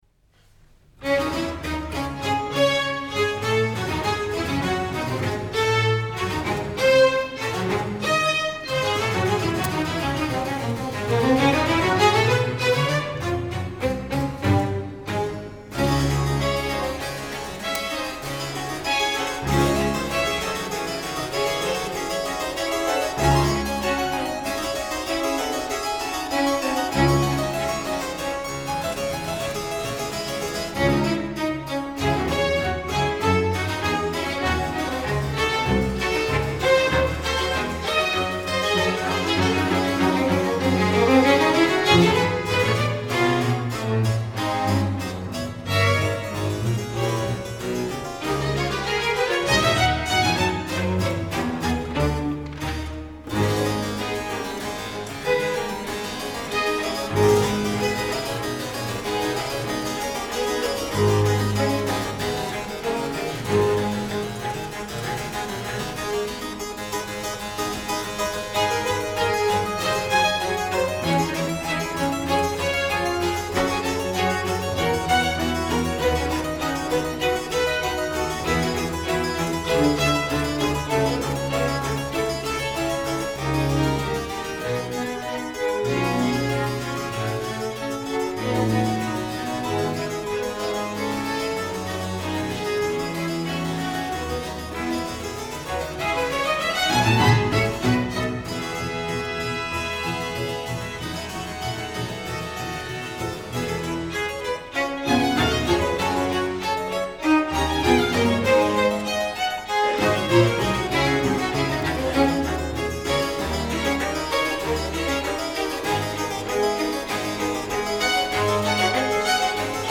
Concerto pour Clavecin – Ensemble Baroque du Léman
Concerto pour Clavecin en ré mineur BWV 1052 – Allegro
Salle Paderewski – Casino de Montbenon Lausanne